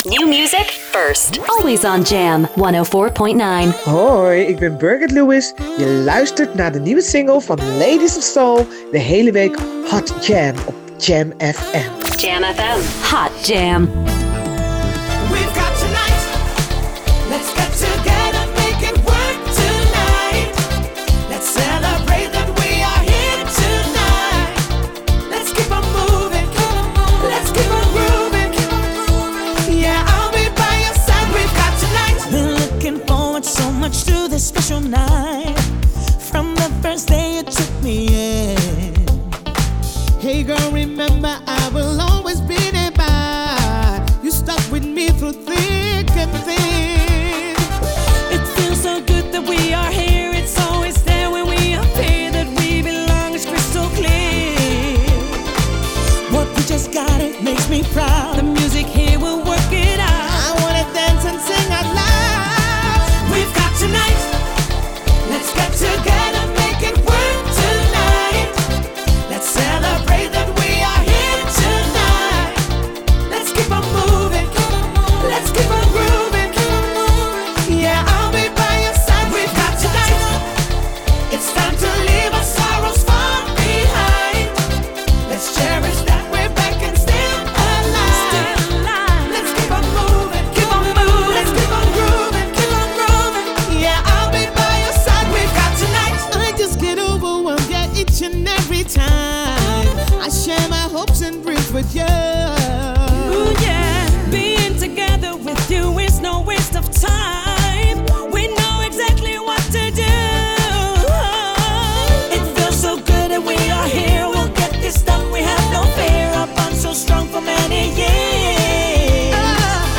saxofoniste